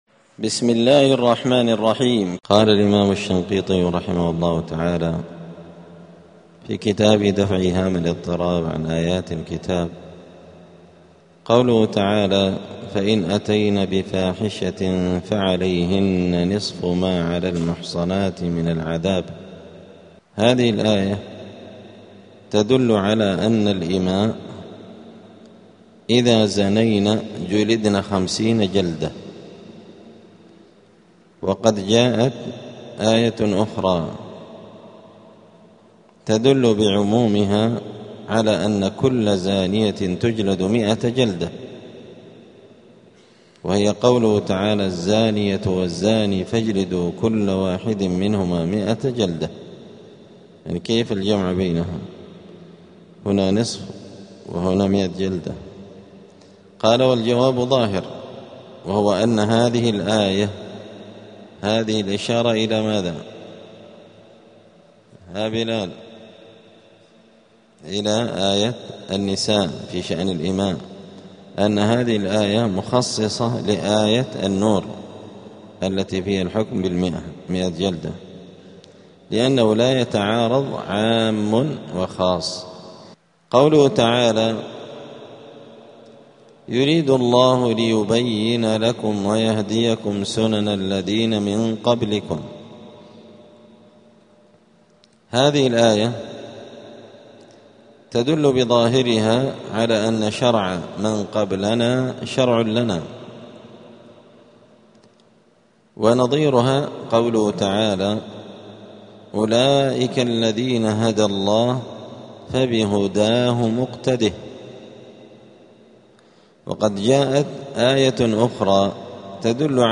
*الدرس الثالث العشرون (23) {سورة النساء}.*